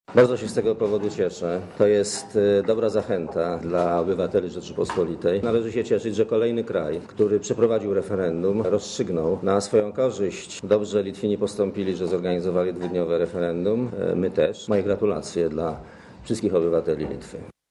Dla Radia Zet mówi premier Leszek Miller (138 KB)